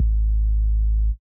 4kik.wav